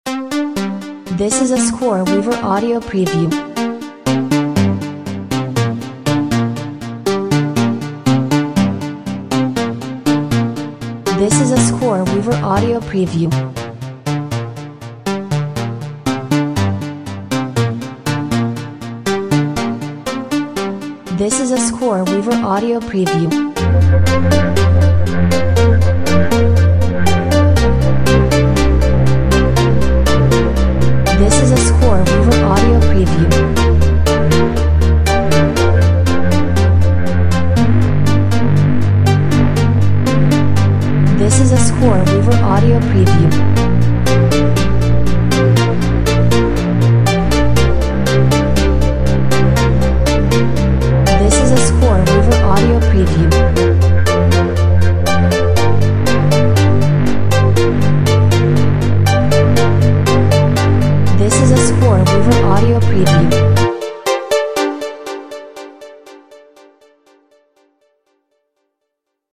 Nicely progressing melodic synth line with a retro vibe!